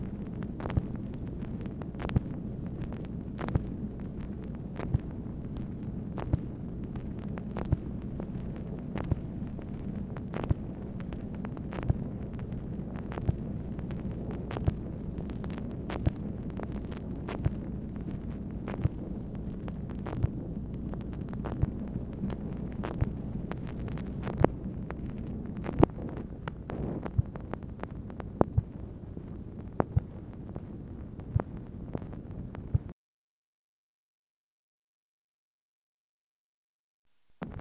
MACHINE NOISE, November 20, 1964
Secret White House Tapes | Lyndon B. Johnson Presidency